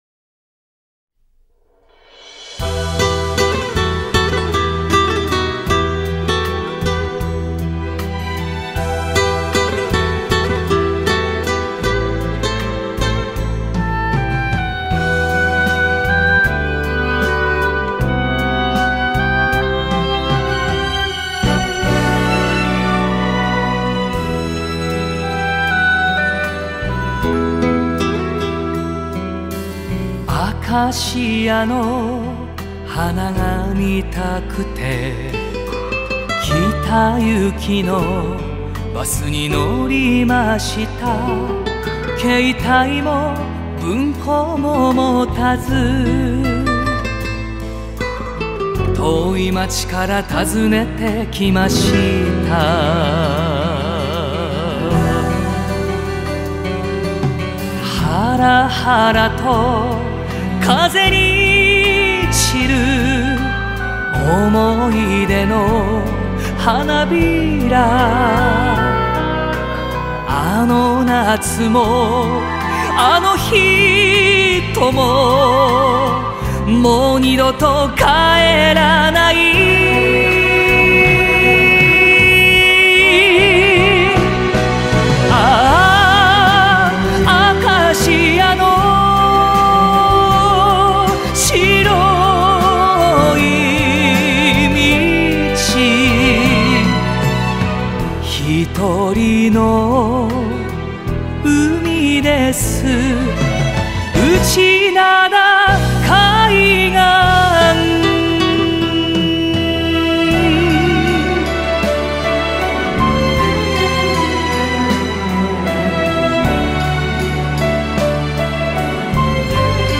Жанр: enka, folk, jpop